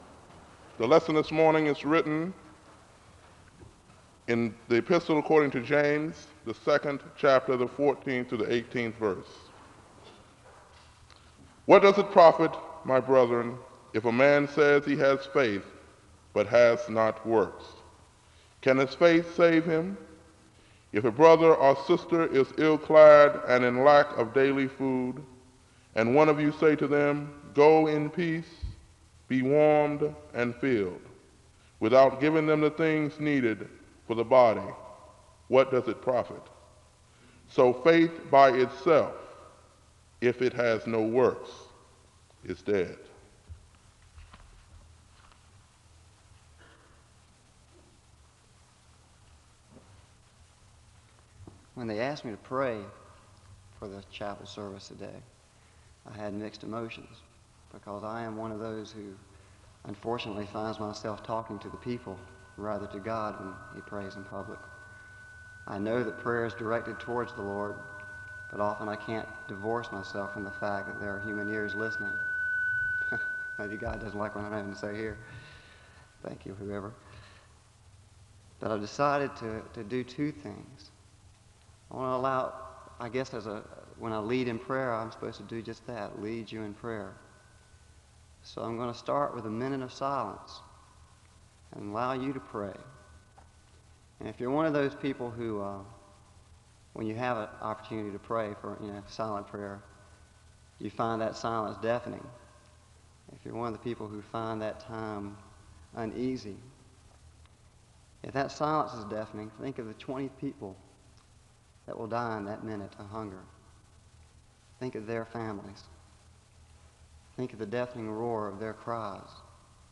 The service begins with a Scripture reading from James 2:14-18 and a word of prayer (00:00-06:55). The speaker gives a few announcements about World Hunger Week (06:56-10:54).
The choir sings the anthem (12:04-16:35).